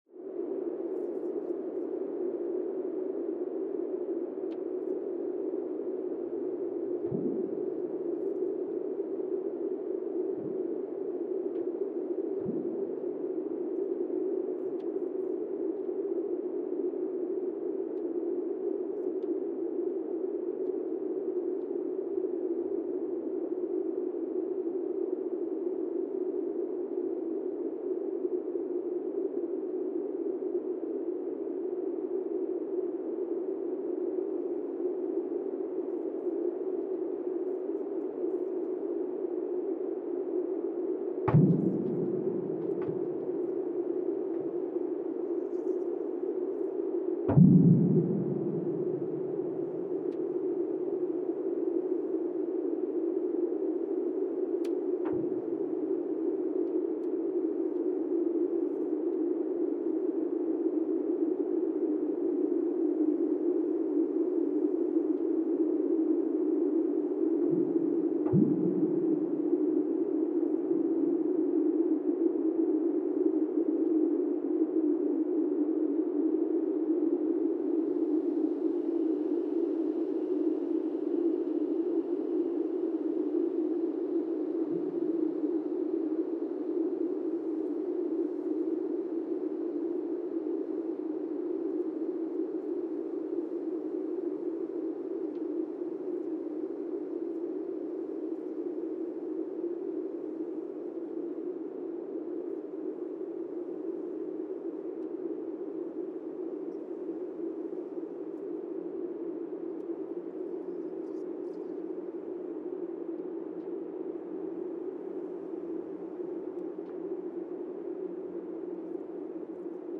Monasavu, Fiji (seismic) archived on March 6, 2021